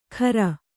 ♪ khara